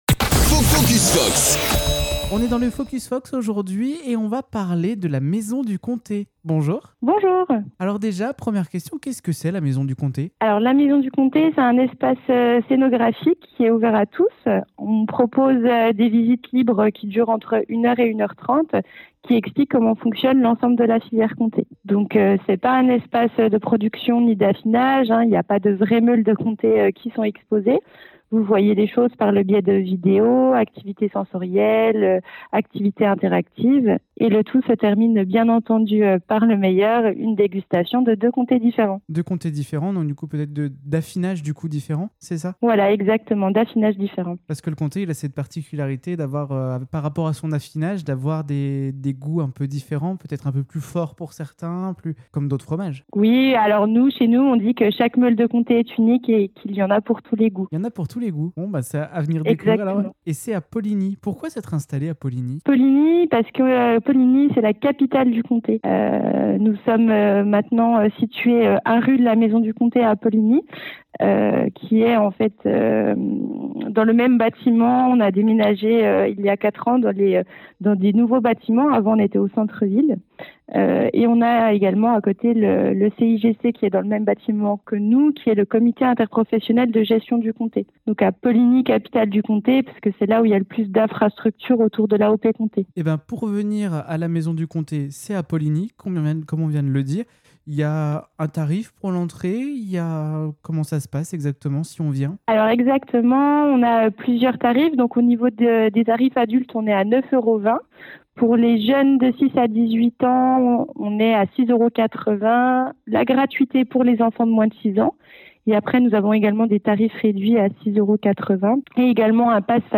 Au téléphone avec l’équipe du musée, nous avons exploré les coulisses de cet espace immersif dédié au célèbre fromage AOP.
Retrouvez notre échange téléphonique avec l’équipe du musée dans ce nouvel épisode Focus Fox, à écouter sur notre site